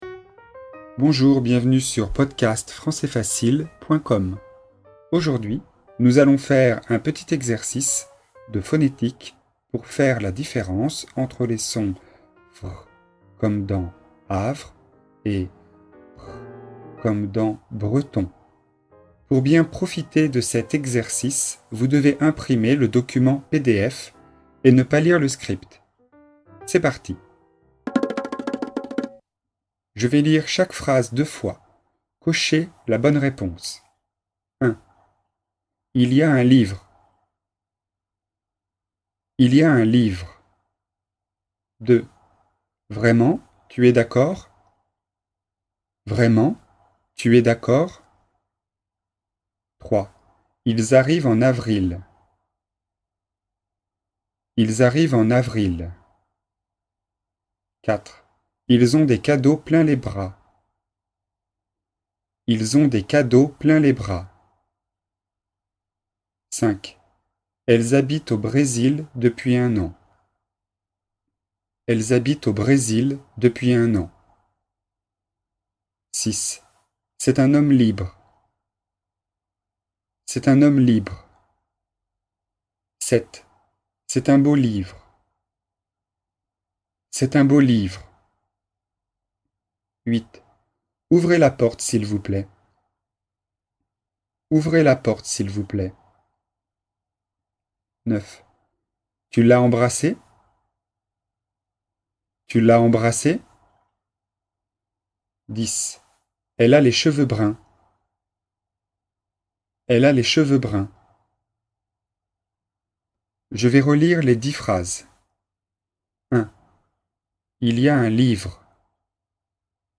Exercice de phonétique et de prononciation, niveau débutant (A1), sur les sons [vr] et [br].